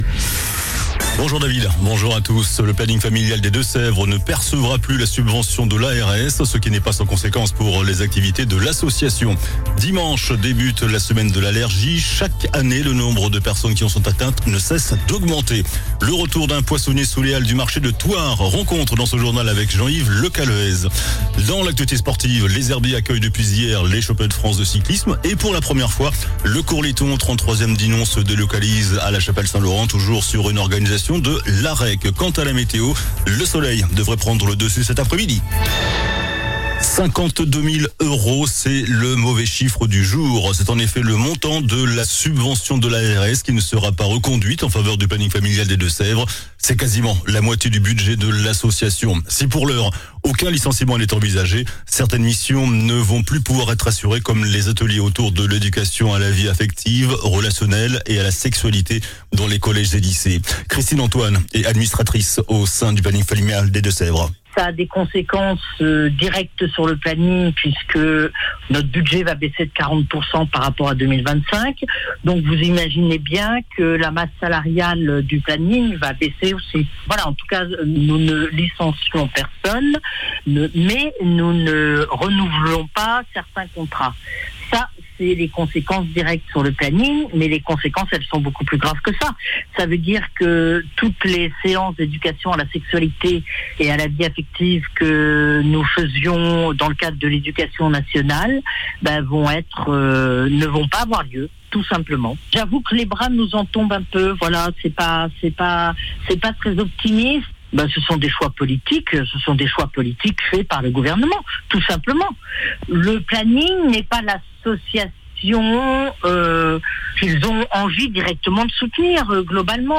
JOURNAL DU VENDREDI 27 JUIN ( JUIN )